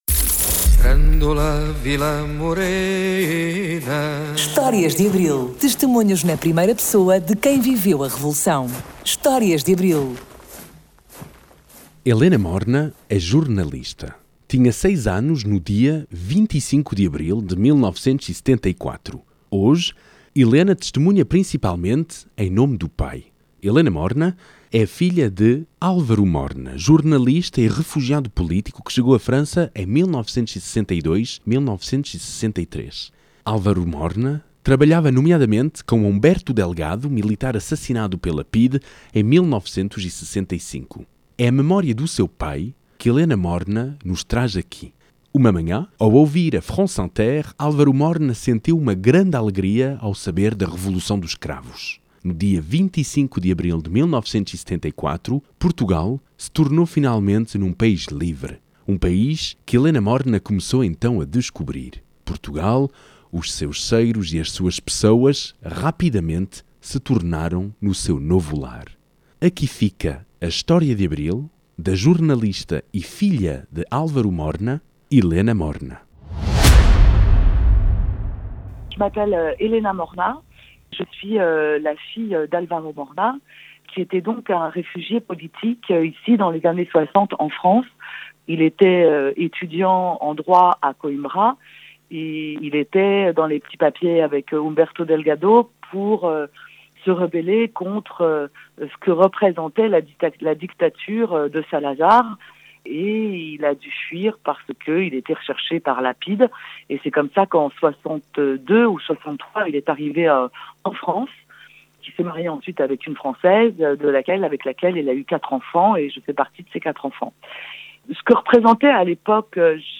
Testemunhos na primeira pessoa de quem viveu o 25 de Abril de 1974.